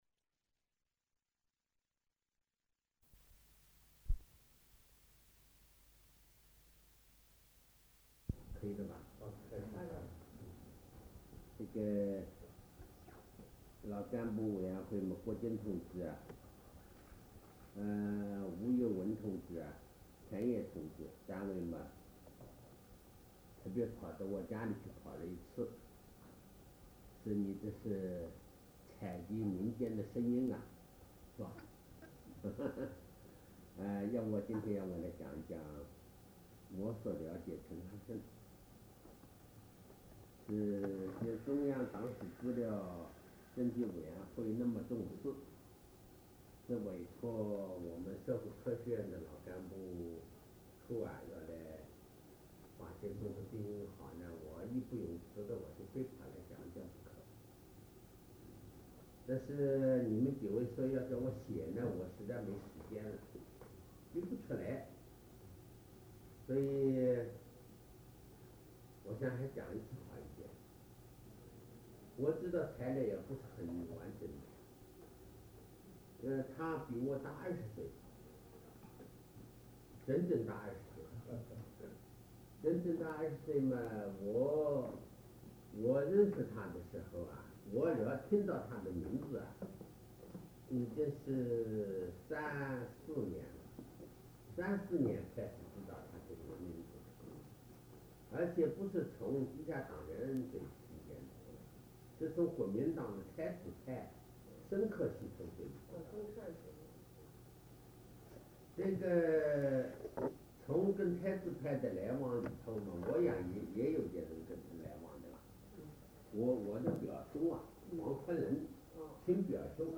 采访录音 | 陈翰笙档案资料库